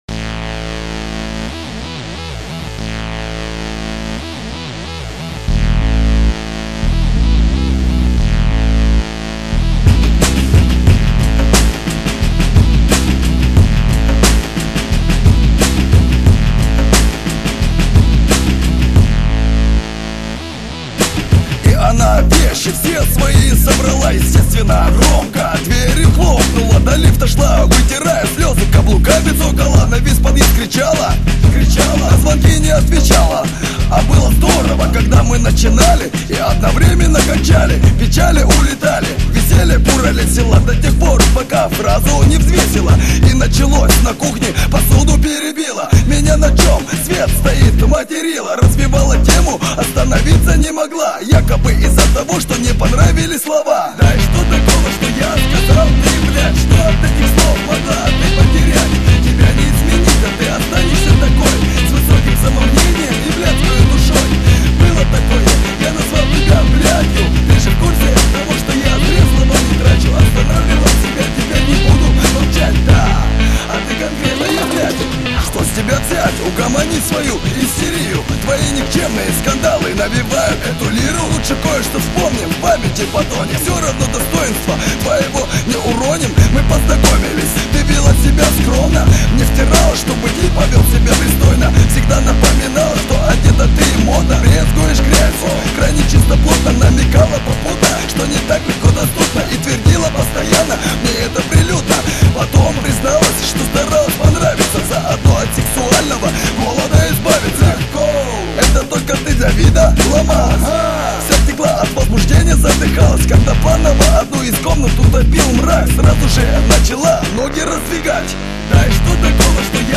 не знаю Рэп